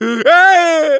bull_die_vo_04.wav